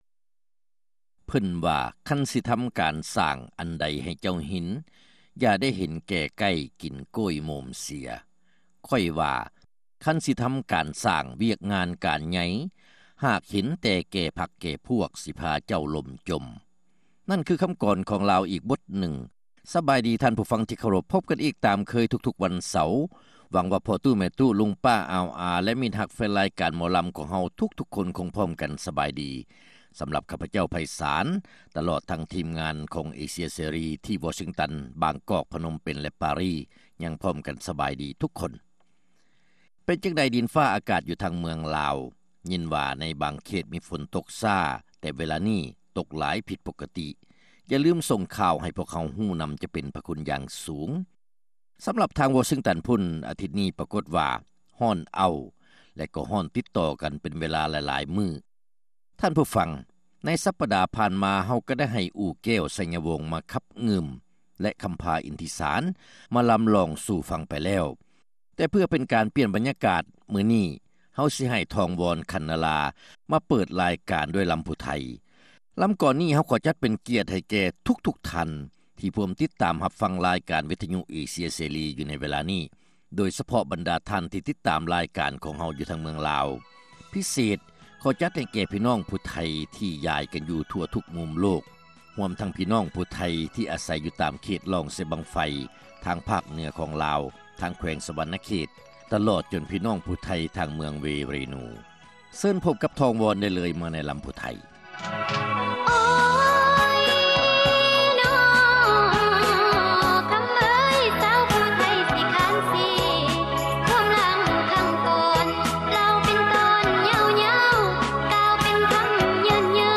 ຣາຍການໜໍລຳ ປະຈຳສັປະດາ ວັນທີ 21 ເດືອນ ກໍຣະກະດາ ປີ 2006